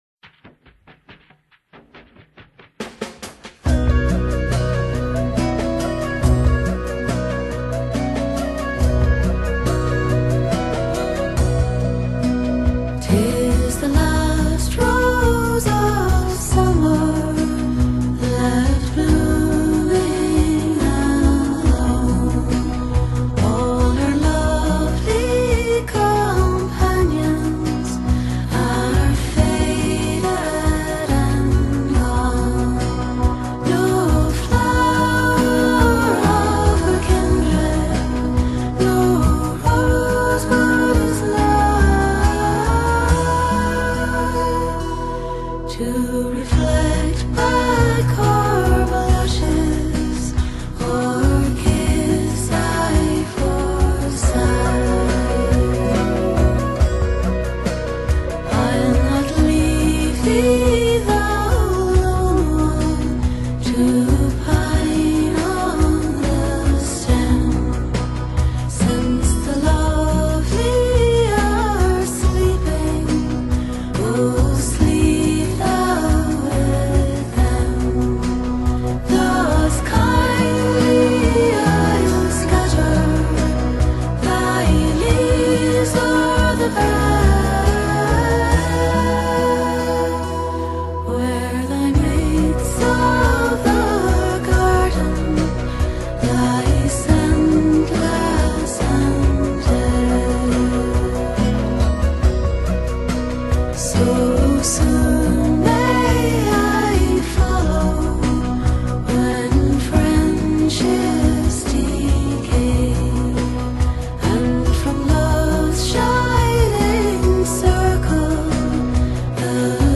Genre: Celtic, Irish Celtic, Vocal